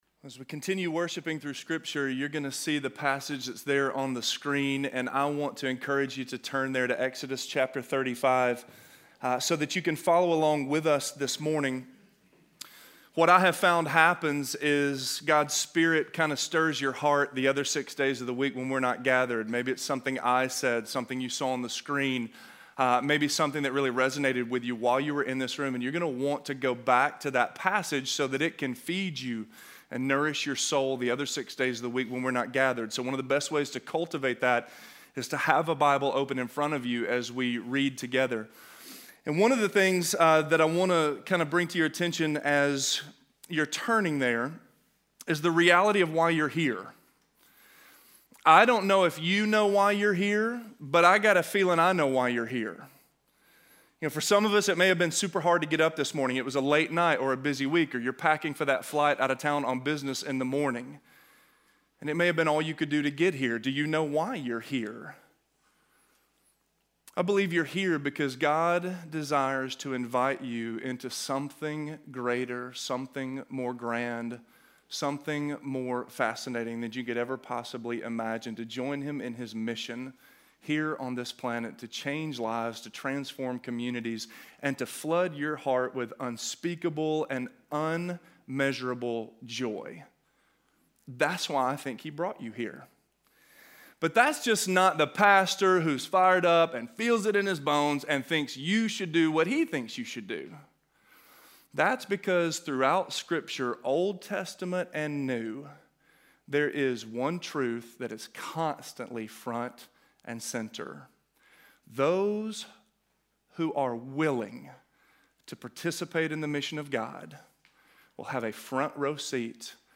Called by Name - Sermon - Avenue South